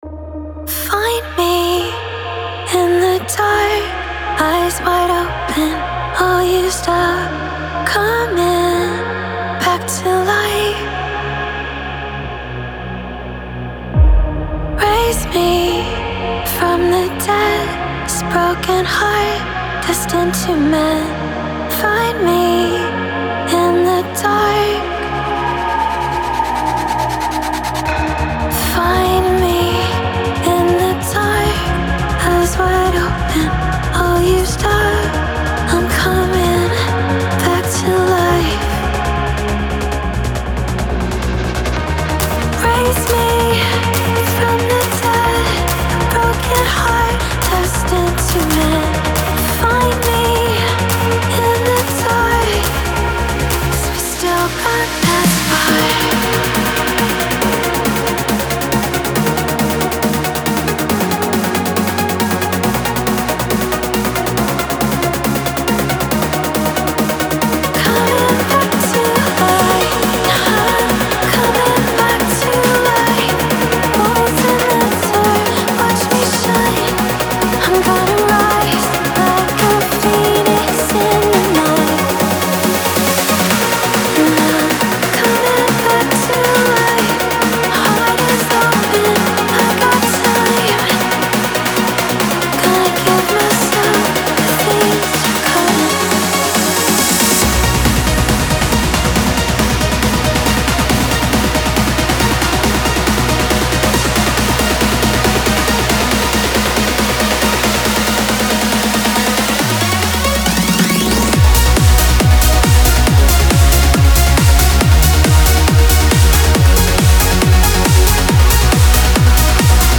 • Жанр: Trance